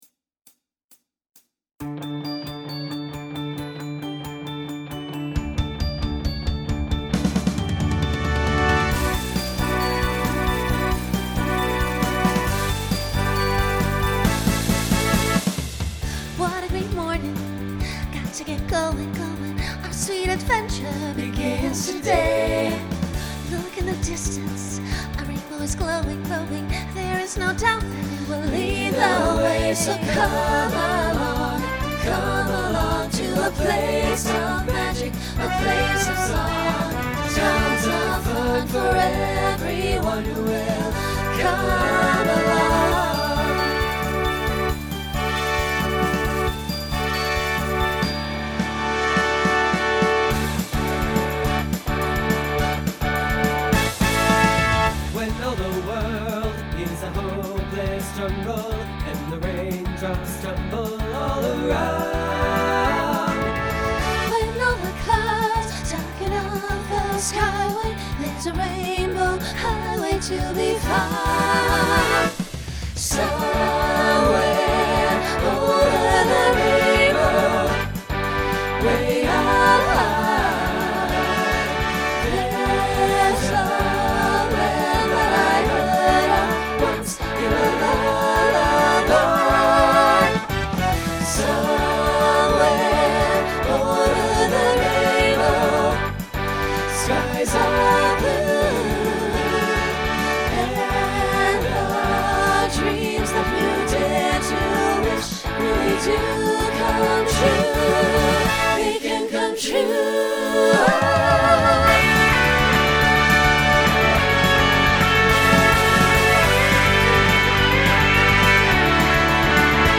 Voicing SATB